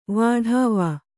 ♪ vāḍhāva